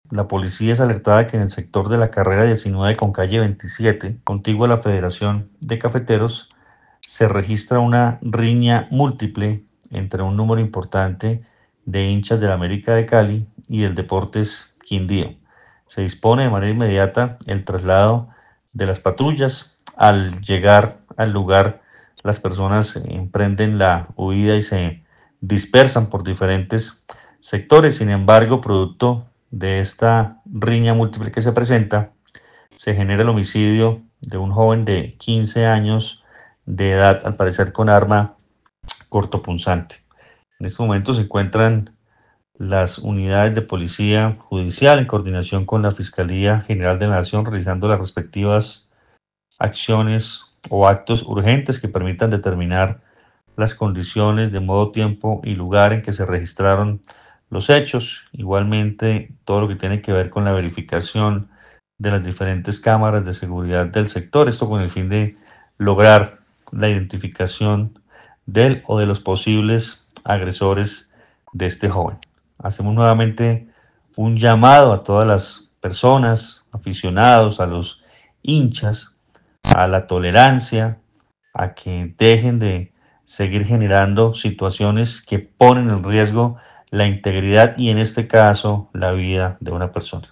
El reporte lo entregó el coronel Luis Fernando Atuesta, comandante de la Policía del departamento quien dio a conocer que se presentó una riña múltiple entre los hinchas y cuando llegan las patrullas tratan de evadir el accionar.